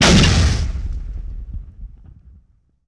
punch3.wav